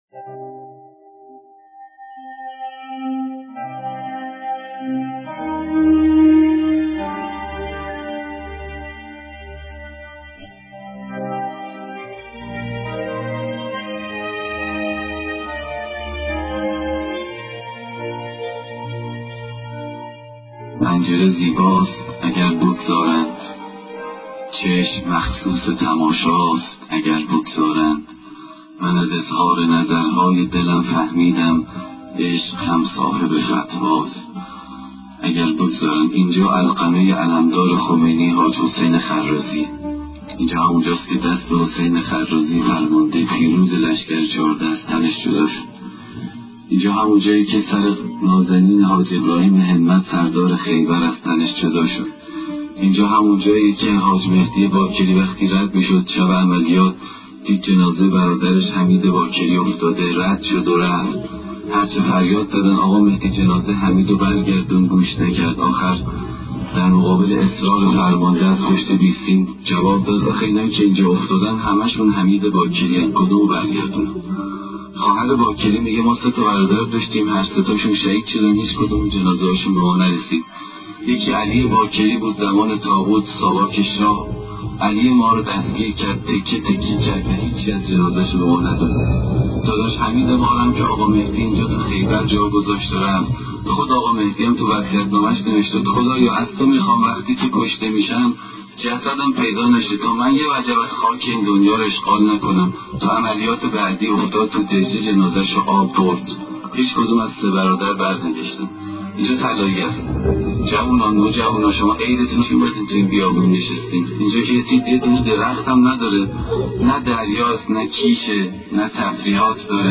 روایتی در مورد منطقه طلائیه معروف به مقر حضرت ابوالفضل عباس
ravayatgari187.mp3